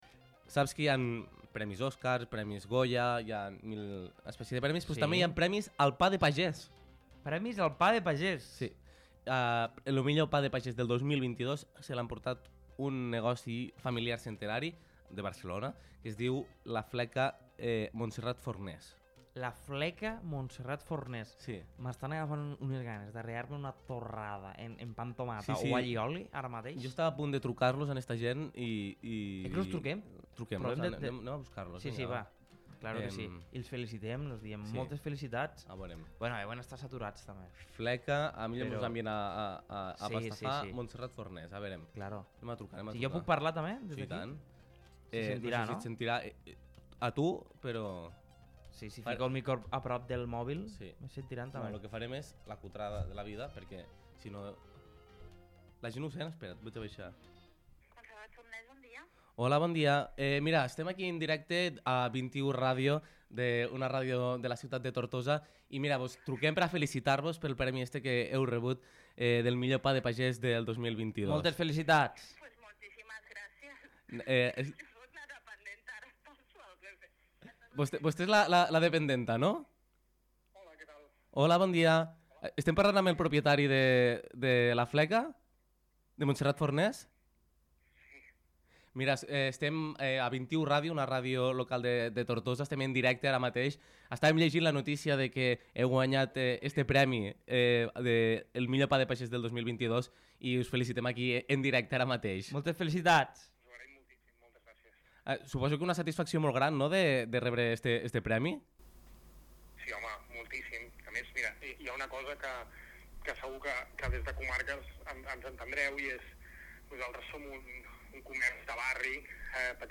Doncs aquesta recepta màgica l’han de tenir Montserrat Forners, una fleca de la ciutat de Barcelona, que han aconseguit el premi al millor pa de pagès de 2022. Al programa ‘De matí manyana’ de 21 Ràdio els hem trucat en directe per donar-los la nostra enhorabona.